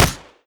poly_explosion_bullet.wav